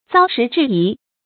遭时制宜 zāo shí zhì yí
遭时制宜发音
成语注音ㄗㄠ ㄕㄧˊ ㄓㄧˋ ㄧˊ